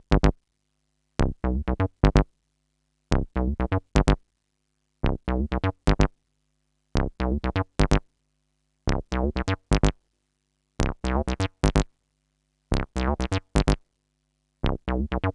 cch_acid_loop_cyclone_125.wav